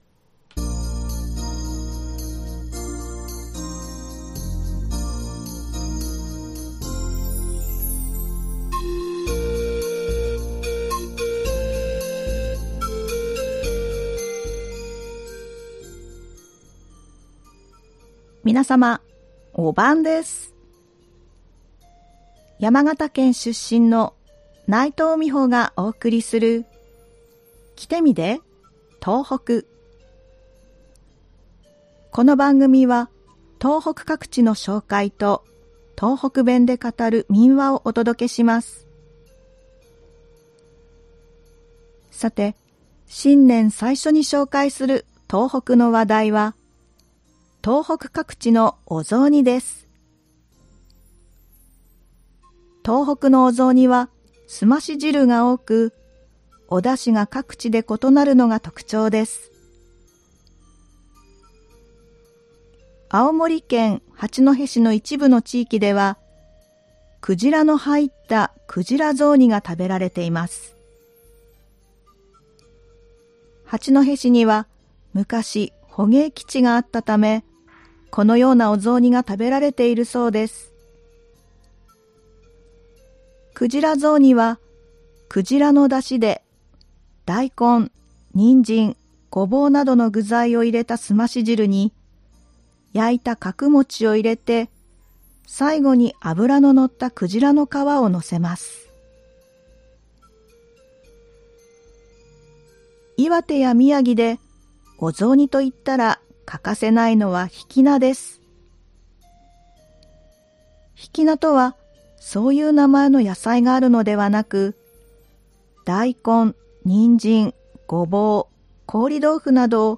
この番組は東北各地の紹介と、東北弁で語る民話をお届けしています。